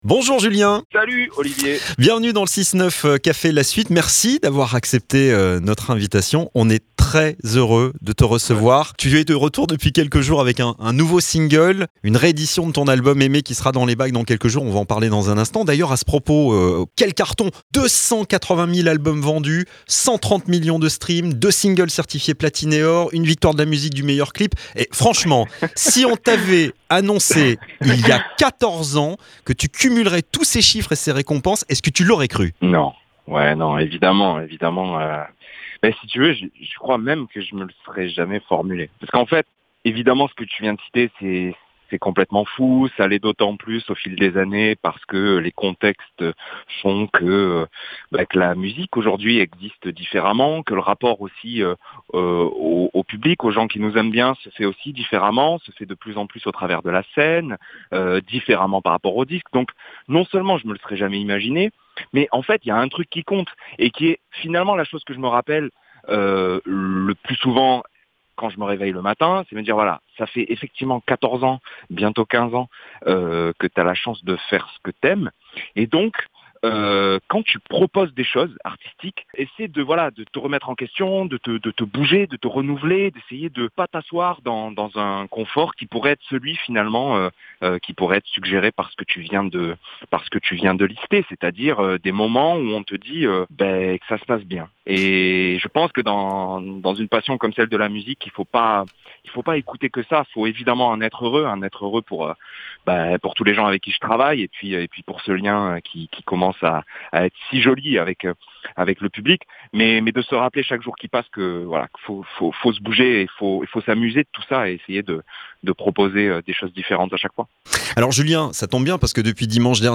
JULIE DORÉ - L'INTERVIEW